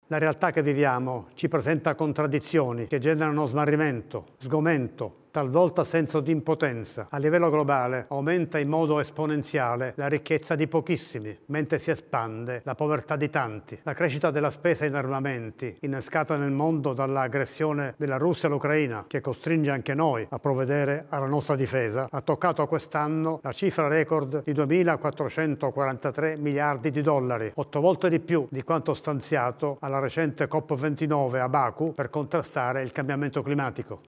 Nel tradizionale discorso di fine anno, il presidente della Repubblica Sergio Mattarella ha più volte sottolineato il valore della pace come diritto irrinunciabile, nel rispetto dei diritti umani, della libertà e della dignità.